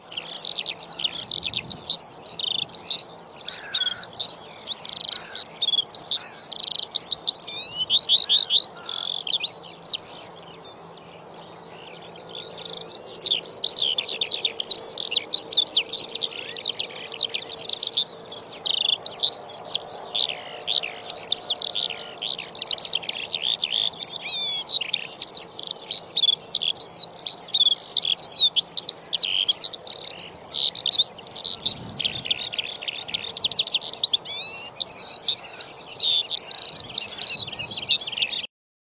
凤头百灵鸟的叫声 “大地上的动人乐章”